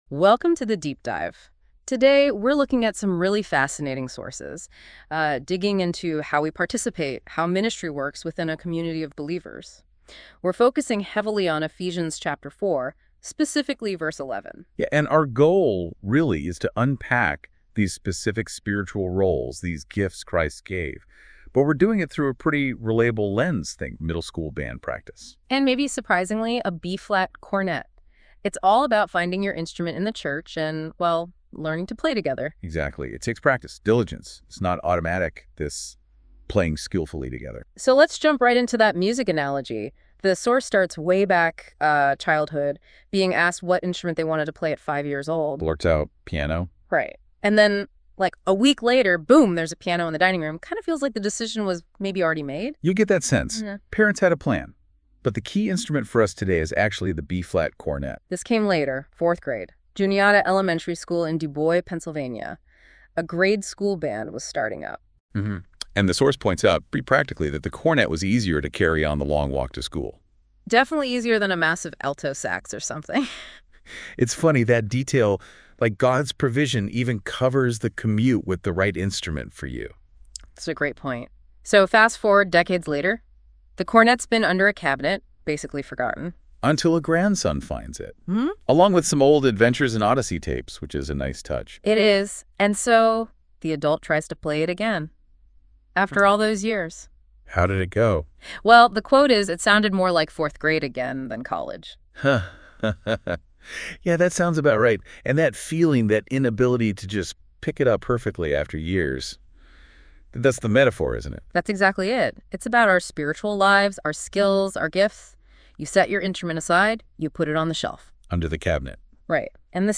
Christ has given gifts to the Church so that it may become all that He desires it to be. The sermon excerpt